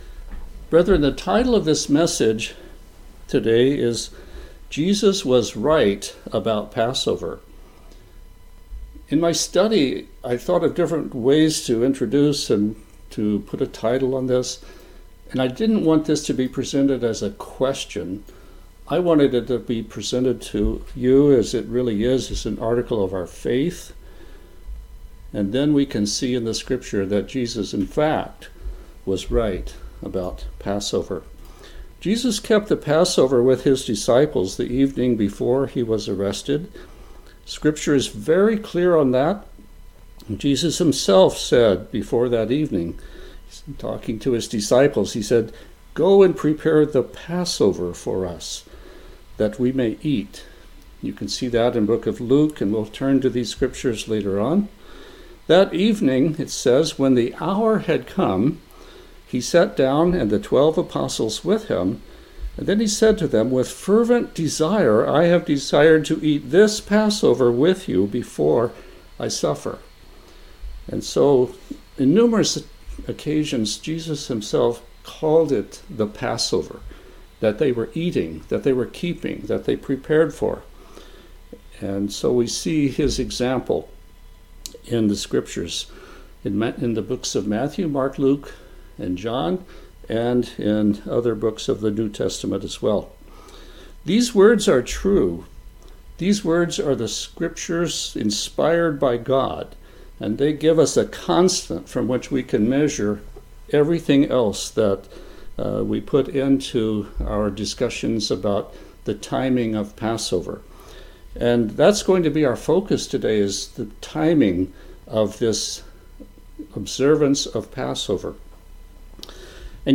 In this sermon, questions about the timing of Passover are asked, and answers are found in the Scriptures.